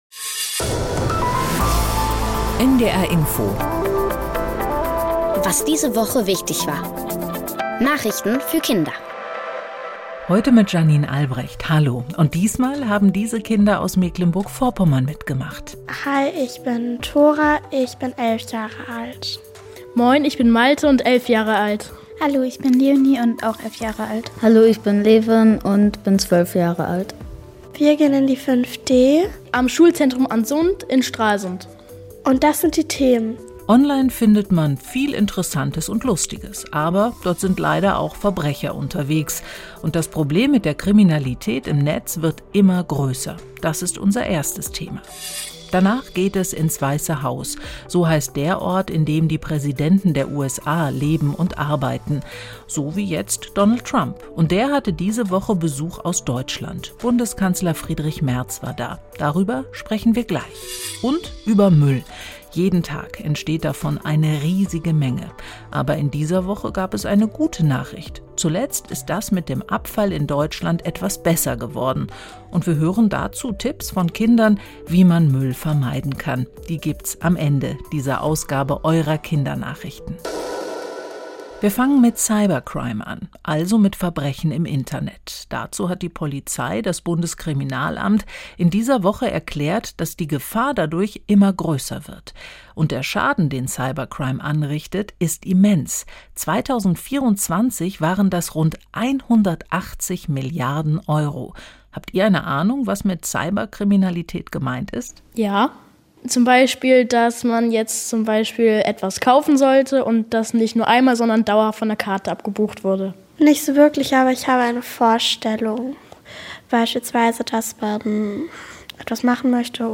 Was diese Woche in Deutschland und der Welt wichtig war erfährst du jeden Samstag hier in den Nachrichten für Kinder von NDR Info. Wir sprechen mit Kindern über die Nachrichtenthemen der Woche und erklären sie verständlich in einfacher Sprache.